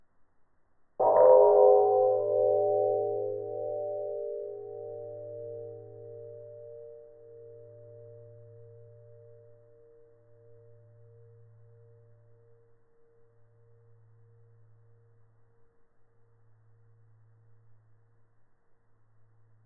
Bell 011
bell bing brass ding sound effect free sound royalty free Sound Effects